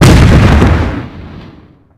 GrenadeExplodeNextRoom.wav